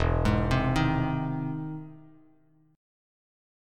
E+M7 chord